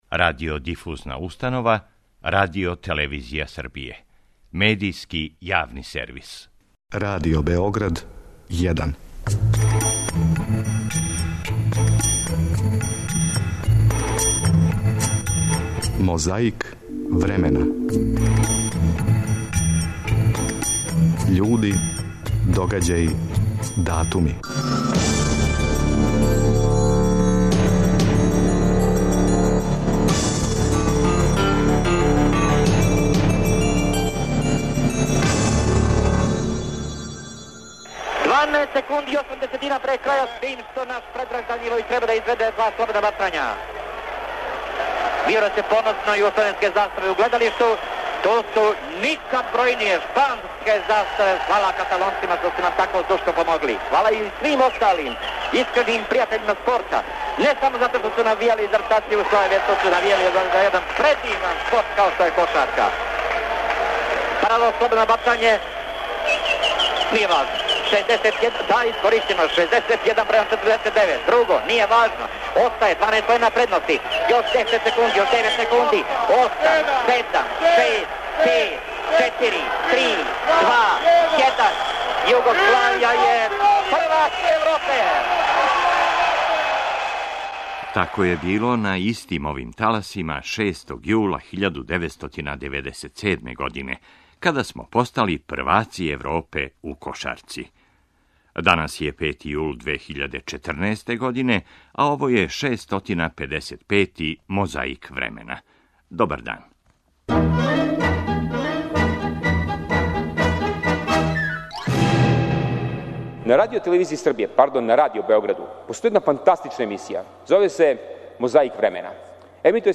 Многи су говорили, а нарочито се истакао народни посланик Војислав Шешељ.
Том приликом, у тим Приликама одржао је и пригодан говор.
Подсећа на прошлост (културну, историјску, политичку, спортску и сваку другу) уз помоћ материјала из Тонског архива, Документације и библиотеке Радио Београда.